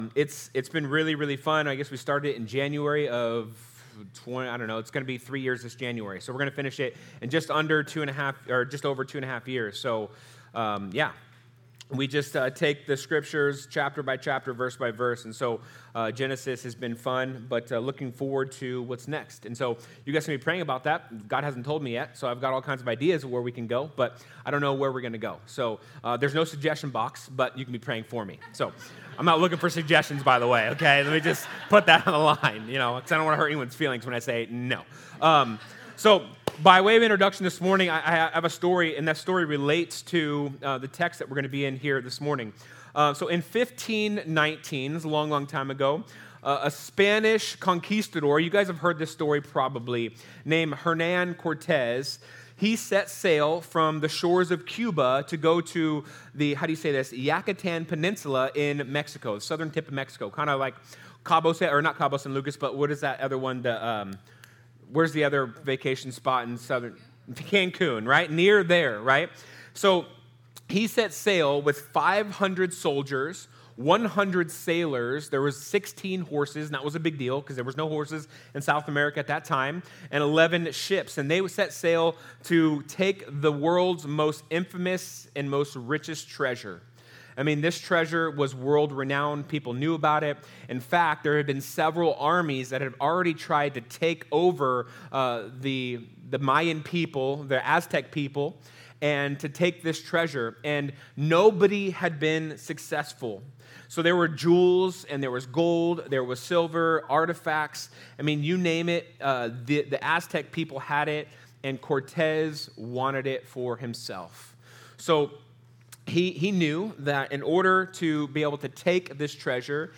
Sermons | The Table Fellowship